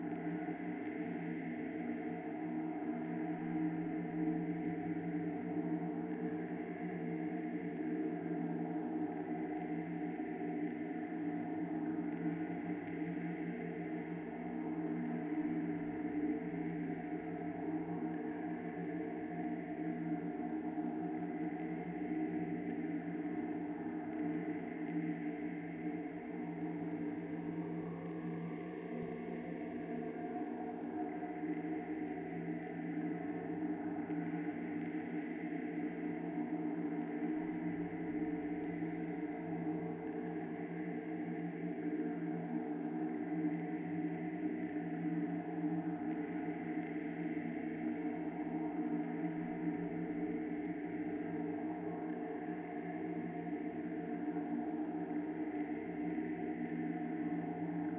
Cave_Loop_03.wav